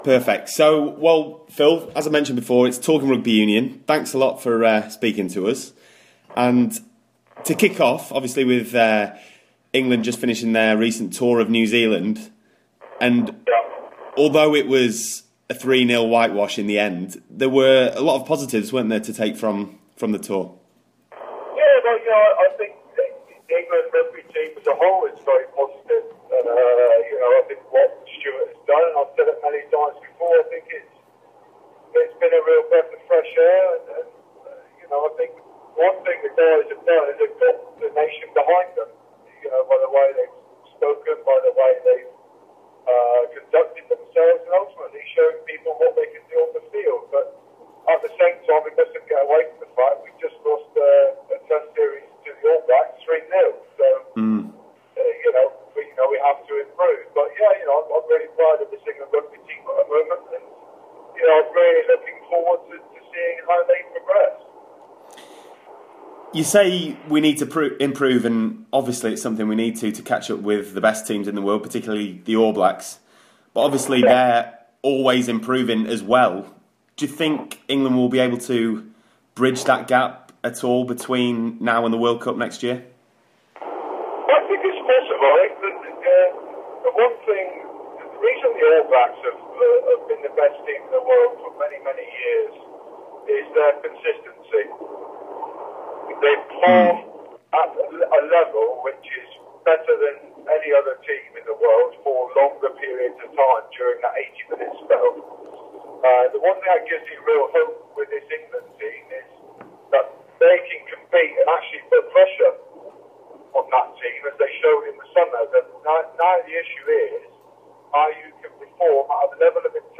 chats to 2003 World Cup winner Phil Vickery about the state of the current England team